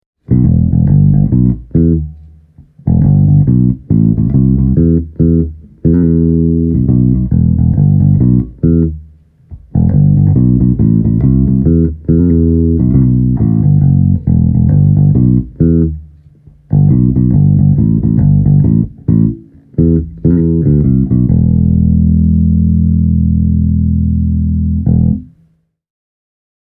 Adding bass while cutting treble takes you straight to Reggae’s swampy realms:
Reggae